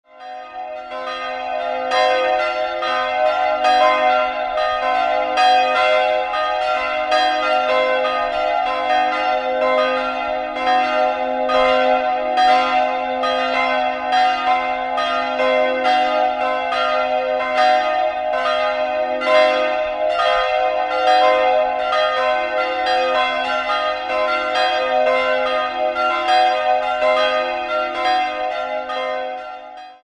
Im Jahr 1956 wurde das Gotteshaus geweiht. 3-stimmiges TeDeum-Geläute: c''-es''-f'' Die drei Glocken wurden 1964 bei Friedrich Wilhelm Schilling gegossen und wiegen 387, 209 und 149 kg.